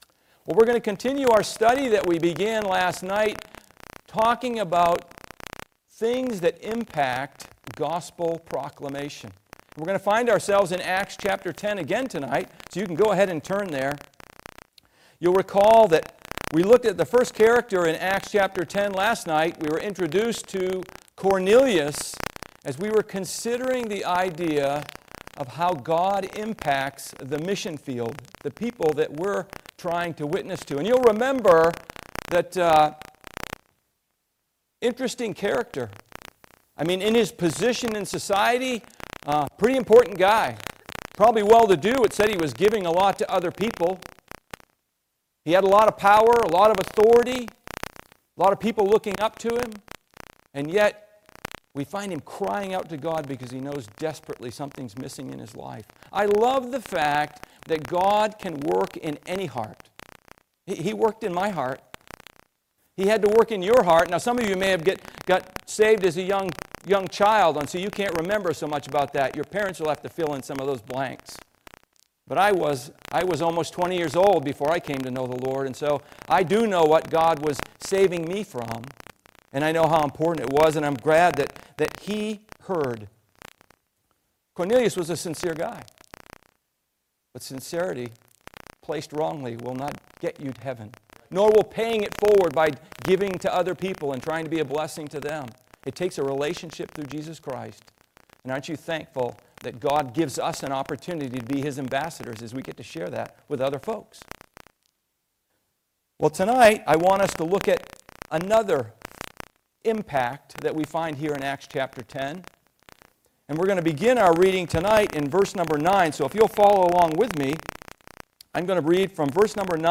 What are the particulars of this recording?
Passage: Acts 10:9-21 Service Type: Midweek Service